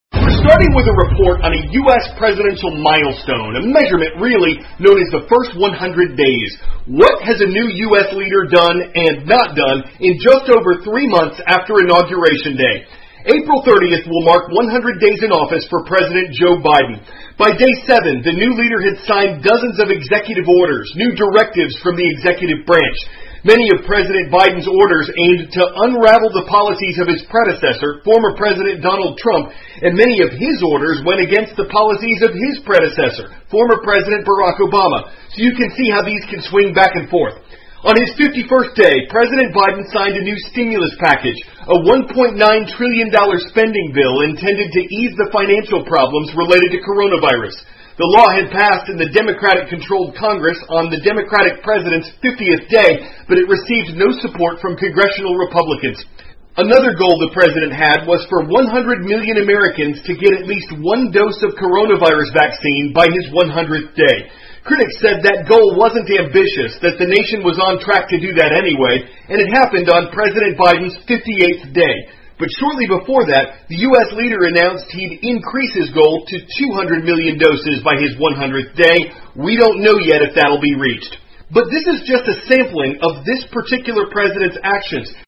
美国有线新闻 CNN 1亿剂目标已达成 拜登着眼上任100天完成2亿剂疫苗接种 听力文件下载—在线英语听力室